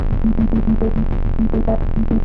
描述：循环的节奏来自Moog rogue和Phototheremin的即兴创作
Tag: 即兴 循环 穆格 光特雷门 样品 空间 老虎